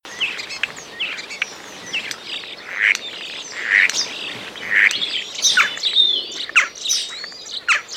chant étourneau A